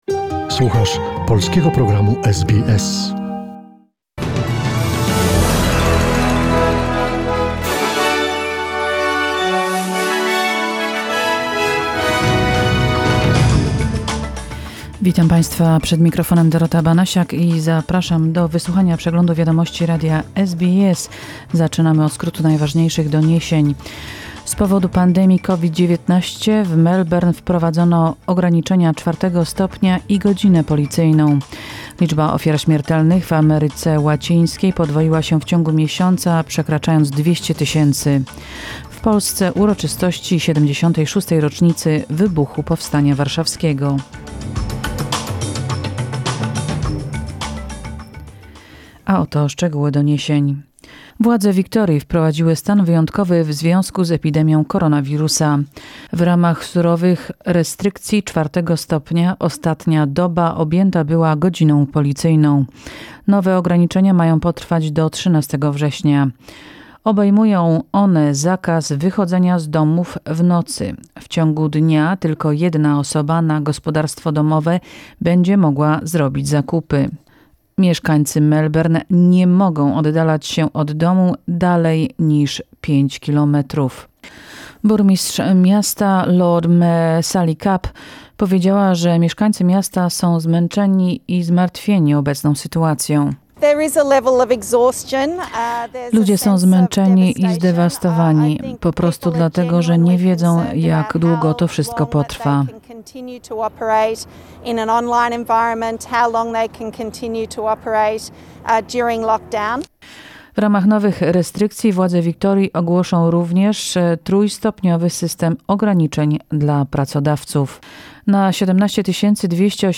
The latest news from Australia, Poland and the world from SBS Polish Program.